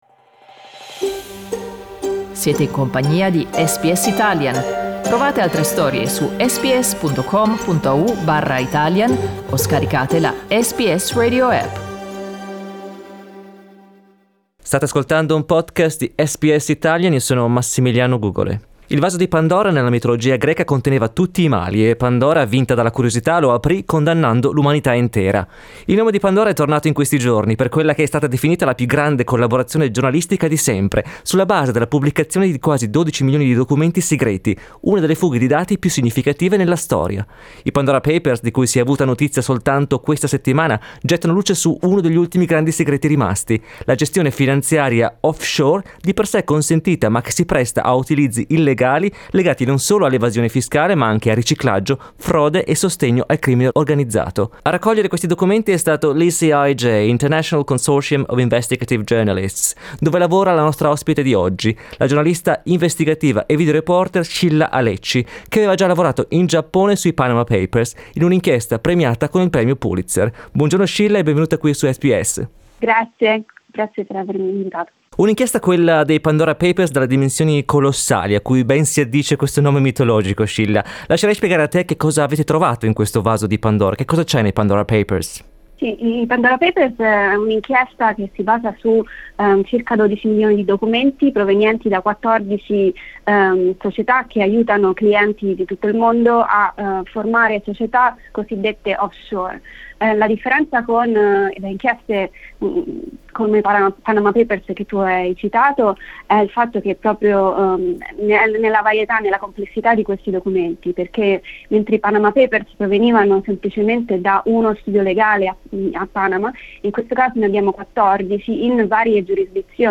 SBS in Italiano